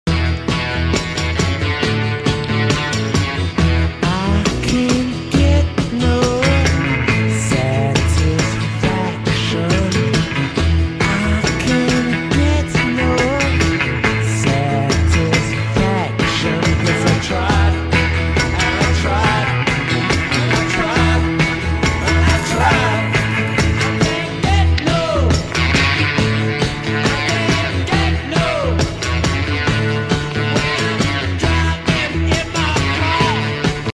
banda de rock inglesa
presenta un riff de guitarra